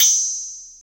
SI2 FMBELL0L.wav